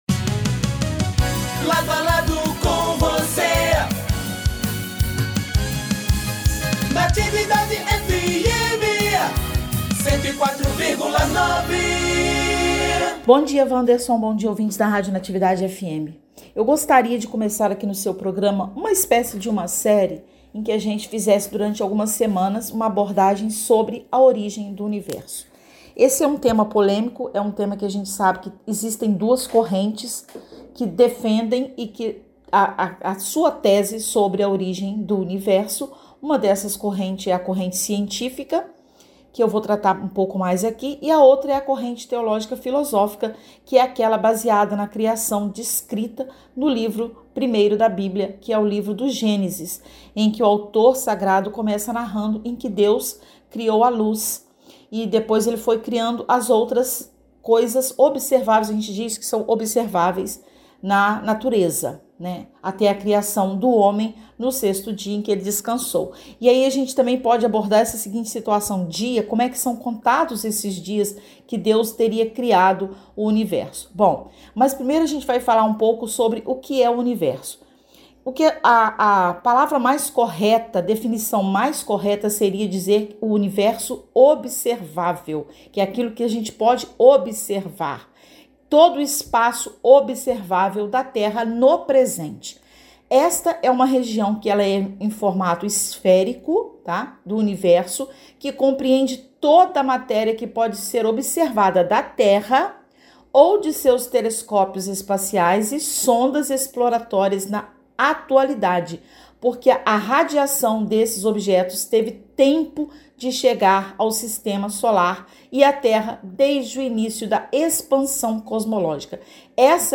20 de junho de 2025 DESTAQUE, ENTREVISTAS